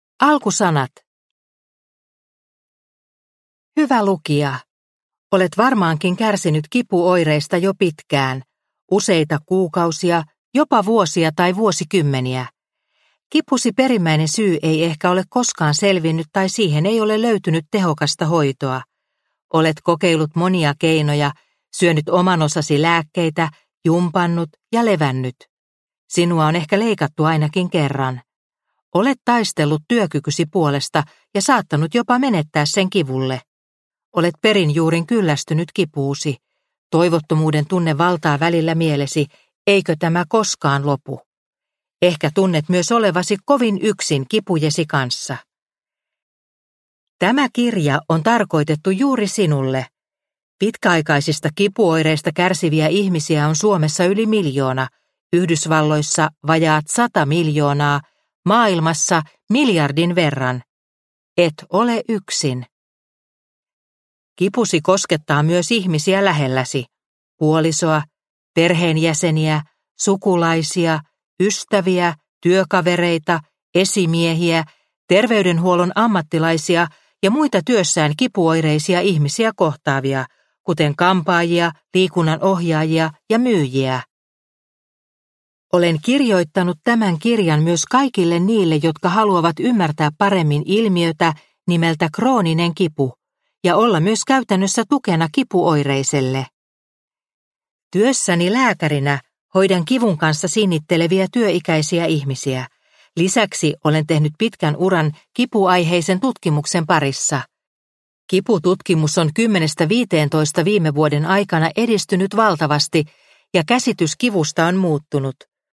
Ota kipu haltuun – Ljudbok – Laddas ner